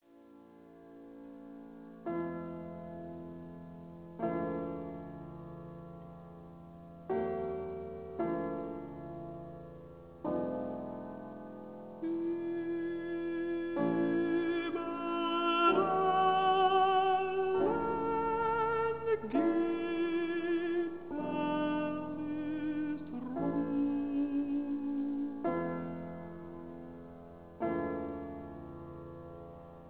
countertenor
piano)hu